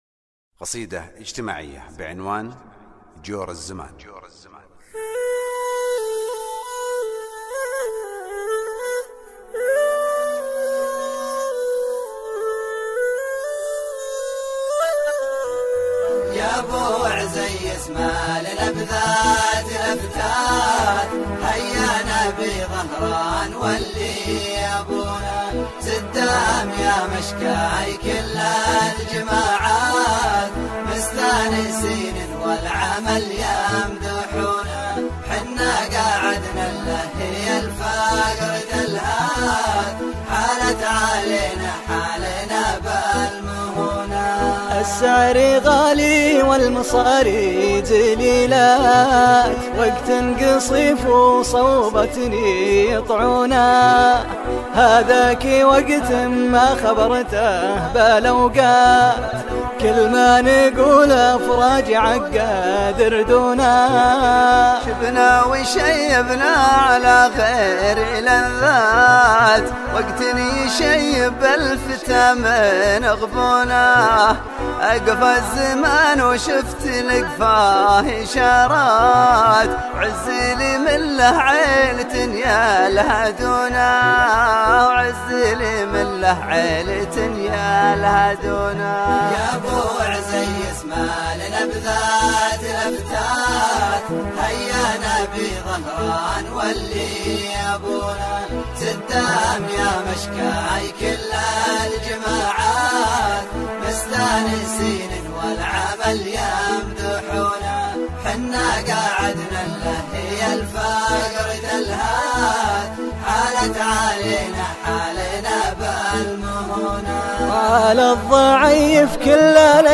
شيلة ..